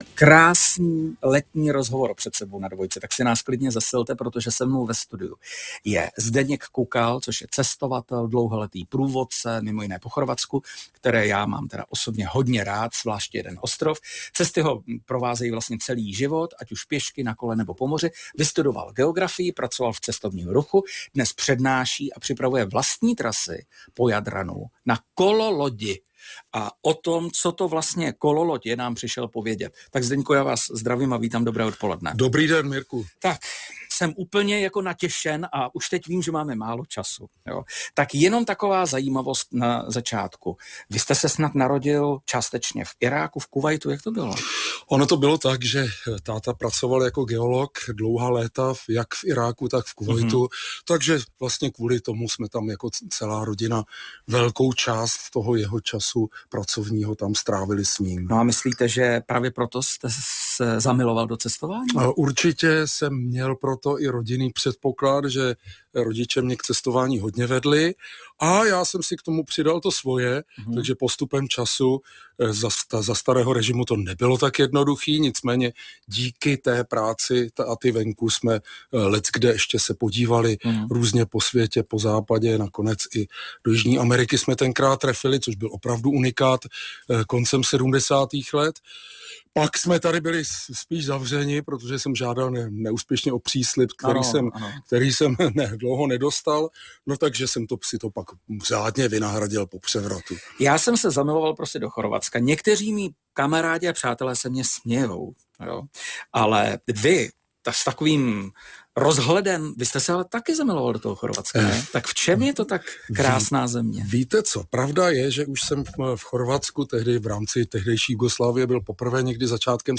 Rozhovor v Českém rozhlase